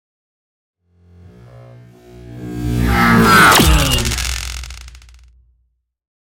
Sci fi shot whoosh to hit
Sound Effects
Atonal
heavy
intense
dark
aggressive